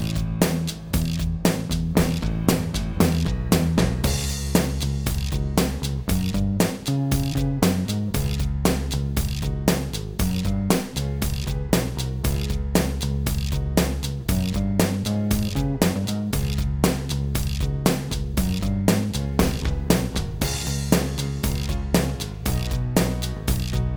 Minus All Guitars For Guitarists 4:43 Buy £1.50